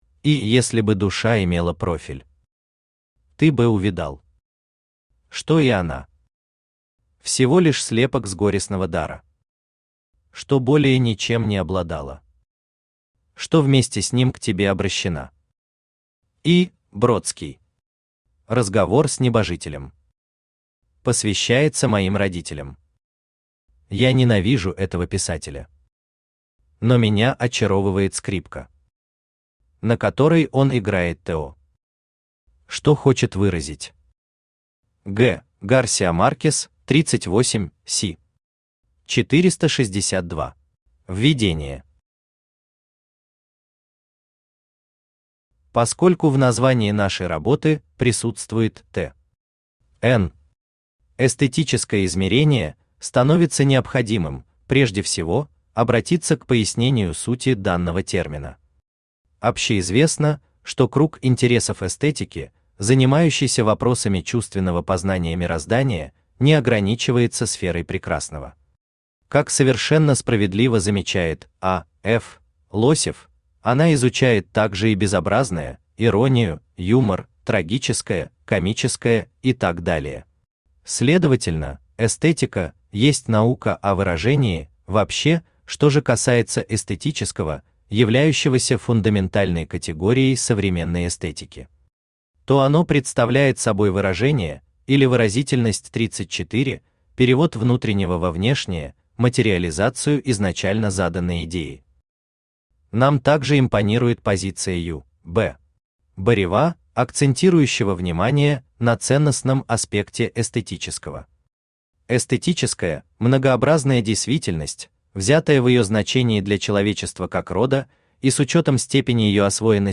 Аудиокнига История музыки в эстетическом измерении | Библиотека аудиокниг
Aудиокнига История музыки в эстетическом измерении Автор Анна Ивановна Маслякова Читает аудиокнигу Авточтец ЛитРес.